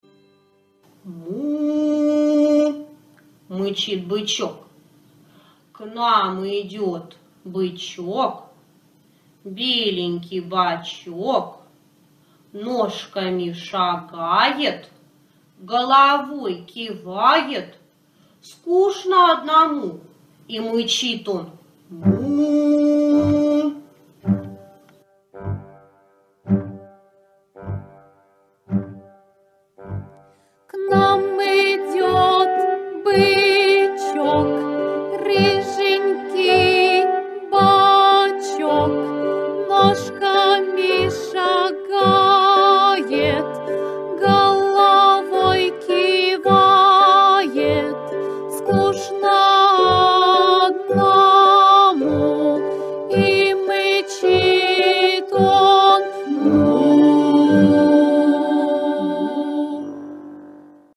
Распевка "Бычок"